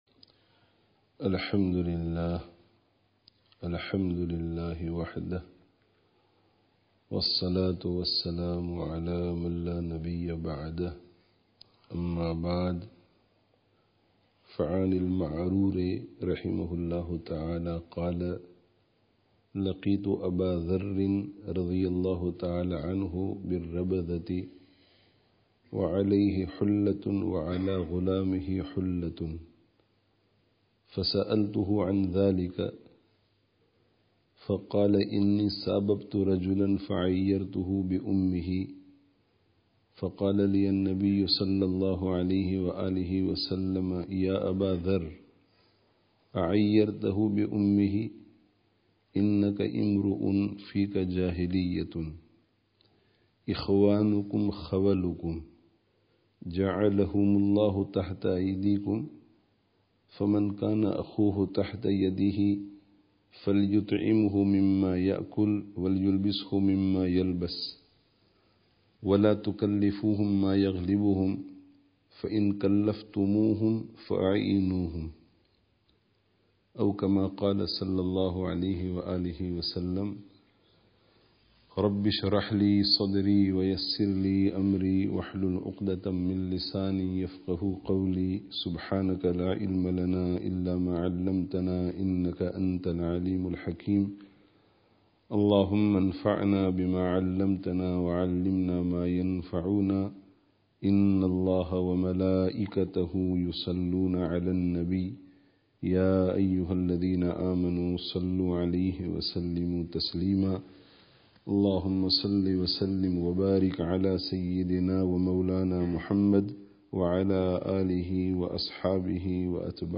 Dars of Hadith